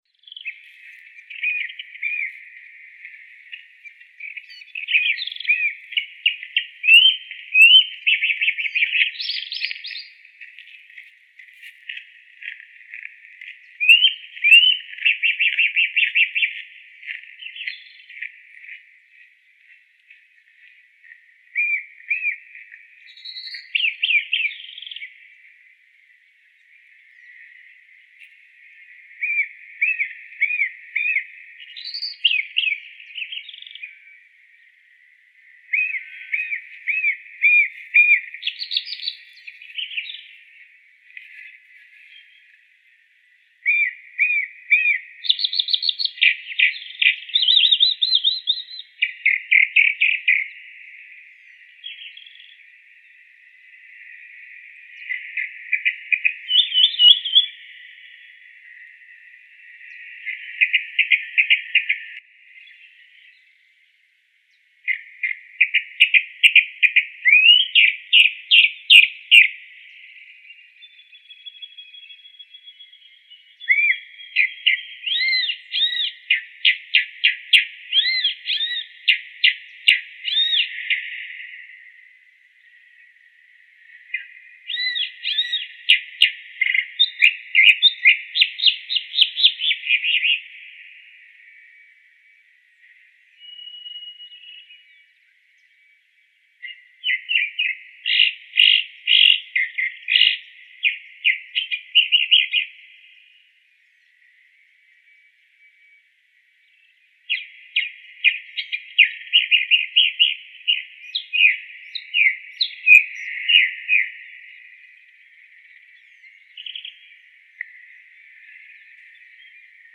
Calandria Grande (Mimus saturninus)
Nombre en inglés: Chalk-browed Mockingbird
Fase de la vida: Adulto
Localidad o área protegida: Reserva Ecológica Costanera Sur (RECS)
Condición: Silvestre
Certeza: Vocalización Grabada